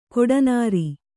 ♪ koḍanāri